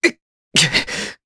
Kasel-Vox_Damage_jp_01.wav